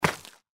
Index of /server/sound/npc/gecko/foot
fs_gecko_l03.mp3